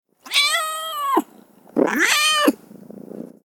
دانلود صدای میو میو گربه گرسنه برای طلب غذا از ساعد نیوز با لینک مستقیم و کیفیت بالا
جلوه های صوتی